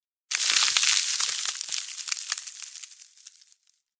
Звуки заморозки
Заморозка человека